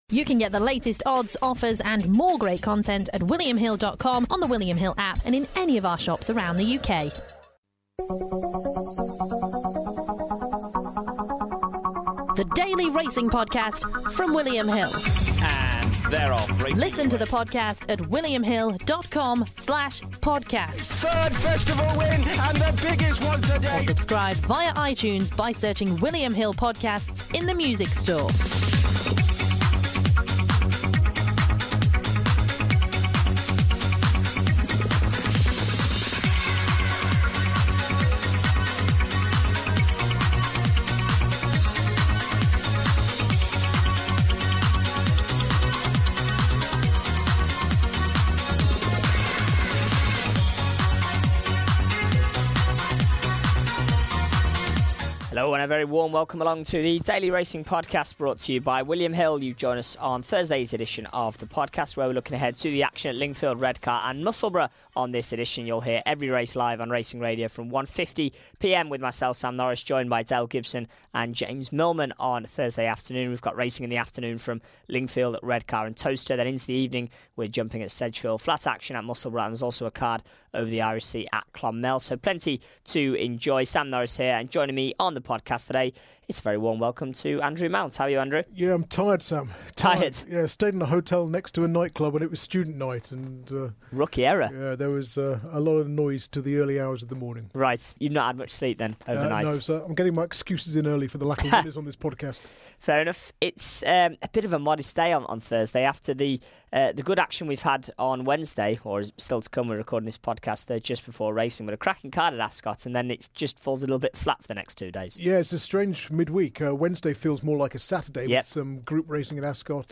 in the studio